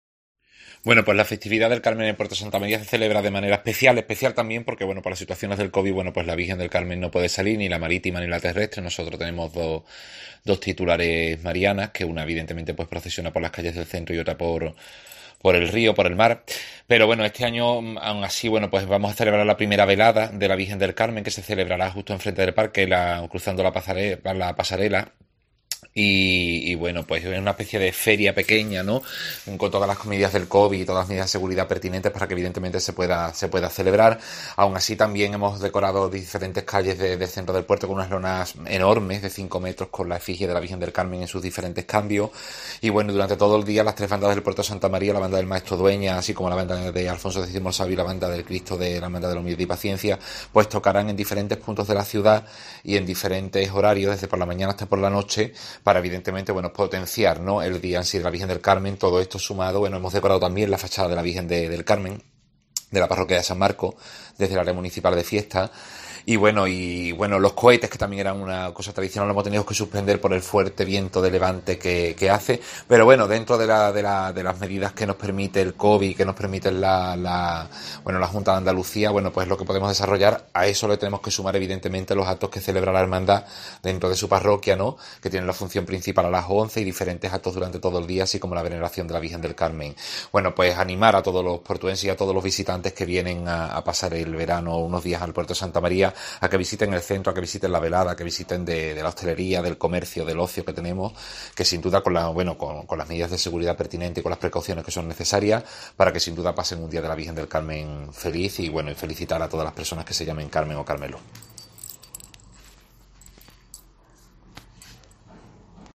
Escucha a David Calleja, concejal delegado de Fiestas del Ayuntamiento de El Puerto de Santa María, sobre la celebración del Carmen